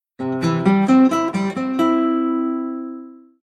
06_Acoustic_Guitar.ogg